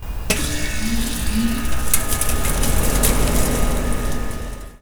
To have a little fun with our demonstration device, we modeled it on an outdoor air conditioner compressor unit and gave it sound effects.
4. Copy three sound effect files by right-clicking each of these links and saving to any convenient temporary location on your PC: